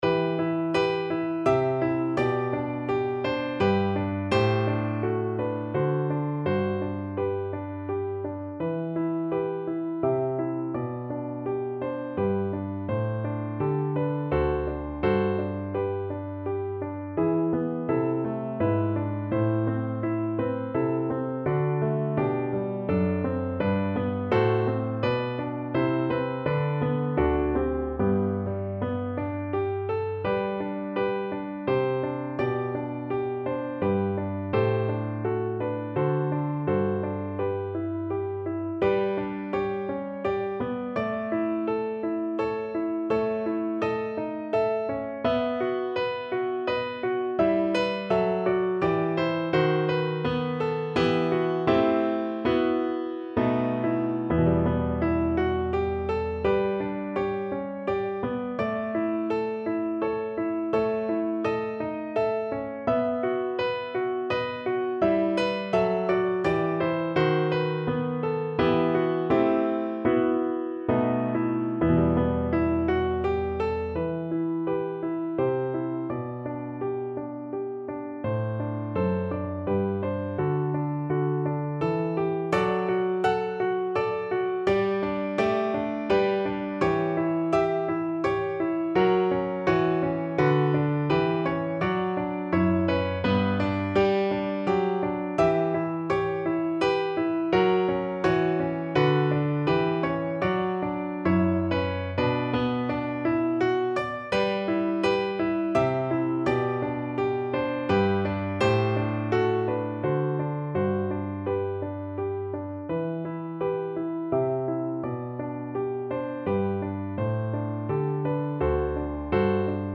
Key: G major
Tempo Marking: Allegretto grazioso = 84
Time Signature: 3/4
Instrument: Piano